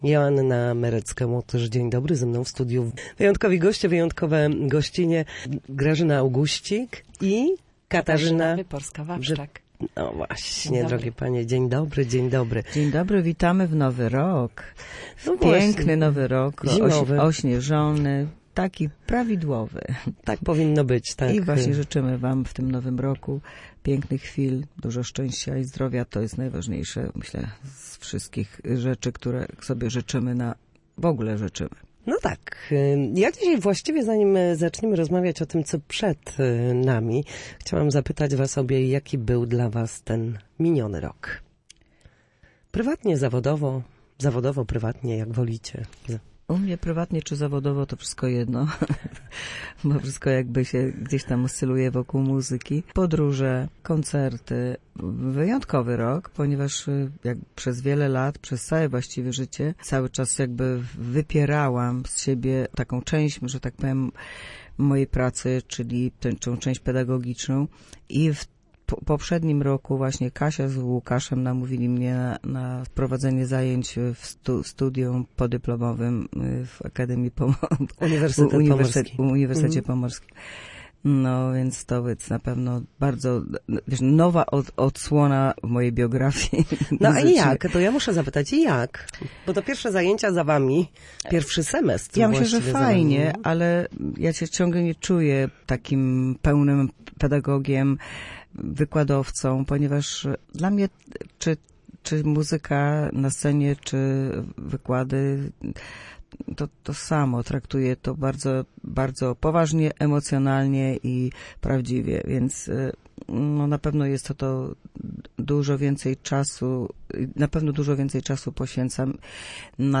Na naszej antenie Panie podsumowały miniony rok oraz mówiły o planach na najbliższe miesiące.